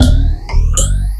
DUBLOOP 06-L.wav